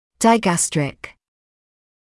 [daɪ’gæstrɪk][дай’гэстрик]двубрюшный; двубрюшная мышца